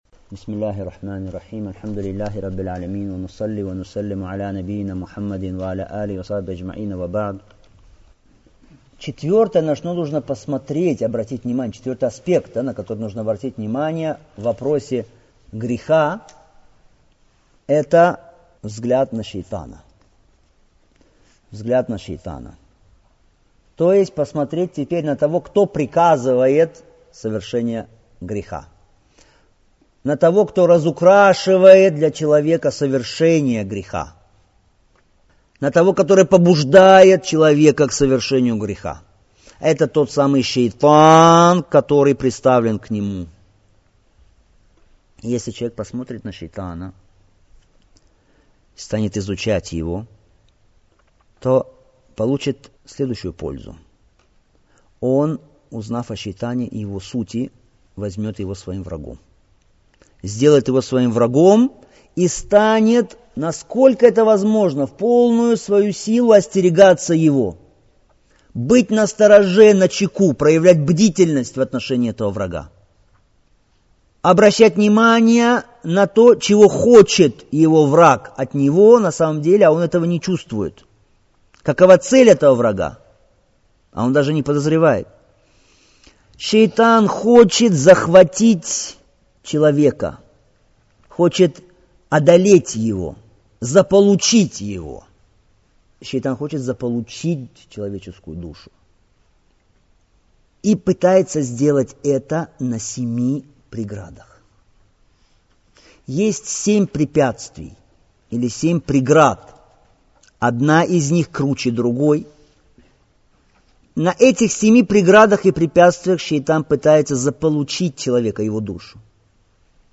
Представляем вашему вниманию чтение книги «Степени идущих» — مَدَارِجُ السَّالِكِينَ — «Мадаридж ас-сааликин» выдающегося мусульманского ученого Ибн аль-Каййим аль-Джаузийя, да помилует его Аллах.